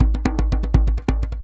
DJEM.GRV01.wav